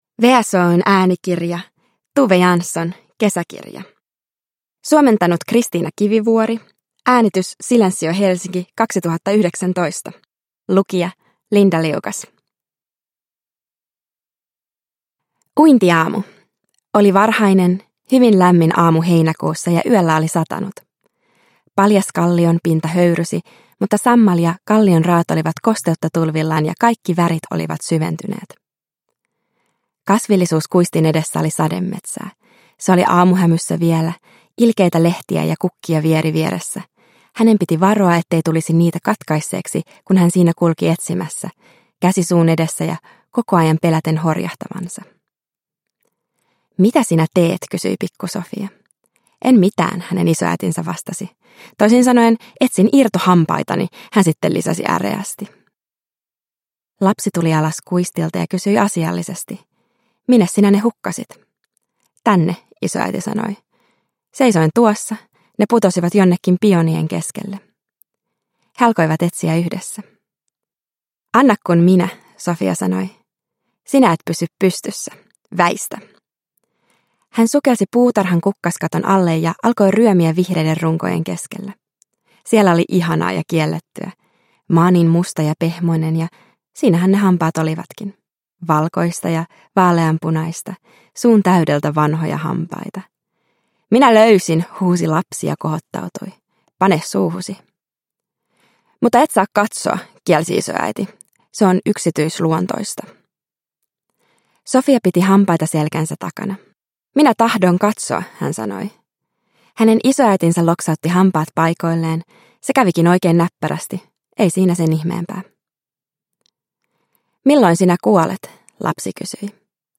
Kesäkirja – Ljudbok – Laddas ner